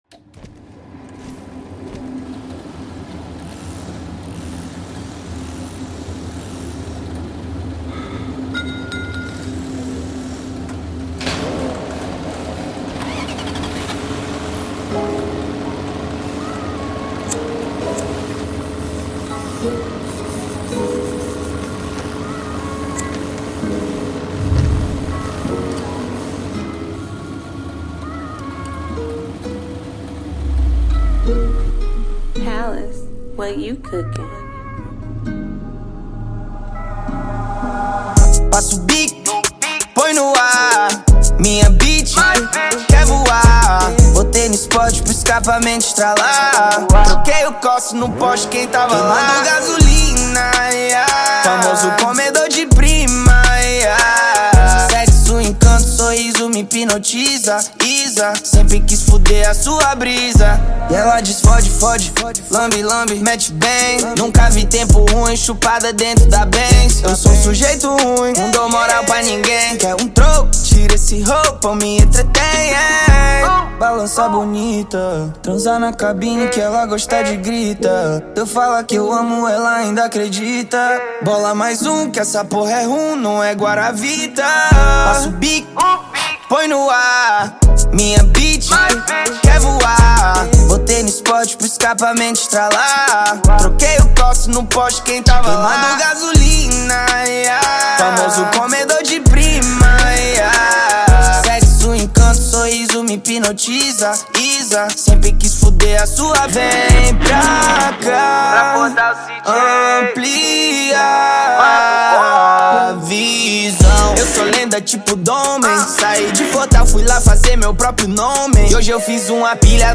2024-04-08 19:01:34 Gênero: Trap Views